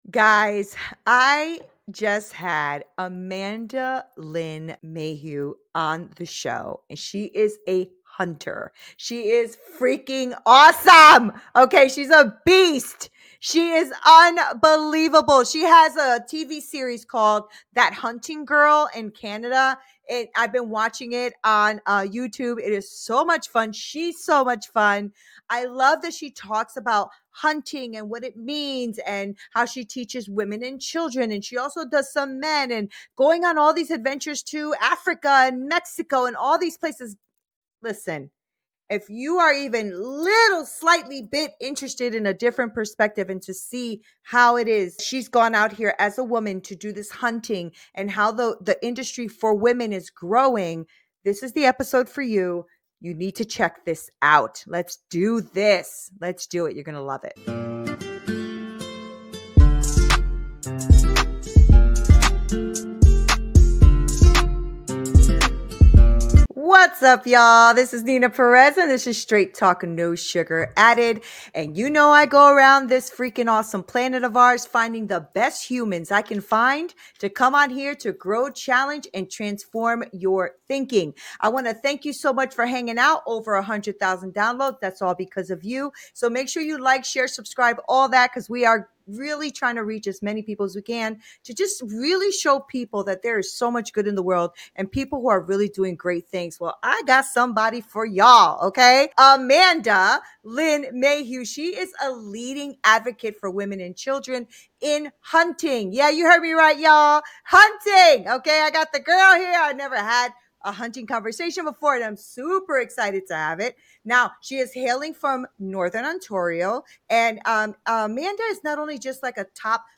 🌟 We talk about everything from the ethics of hunting to empowering women in the outdoors. Join us for an inspiring and fun conversation that will challenge and transform your thinking.